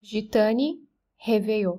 If you’re wondering about how to pronounce it,